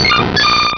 Cri de Leuphorie dans Pokémon Rubis et Saphir.